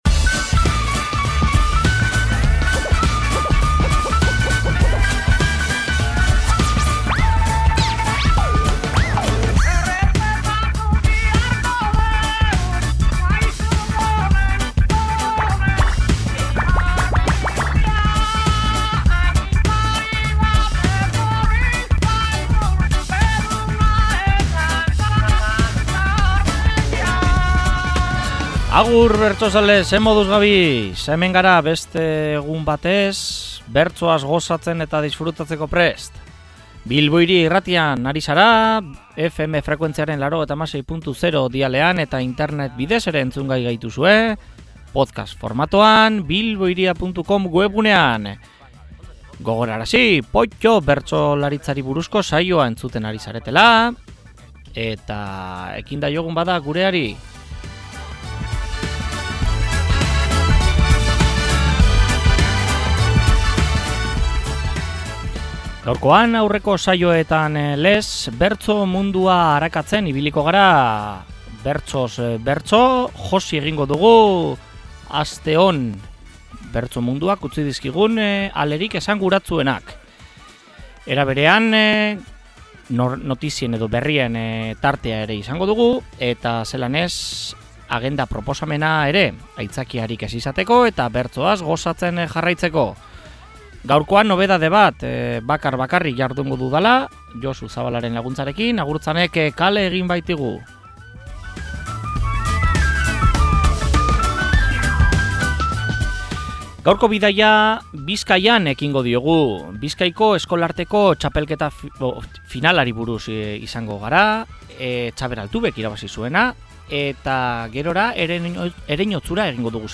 POTTO: Bertsotan blai Euskal Herrian barna.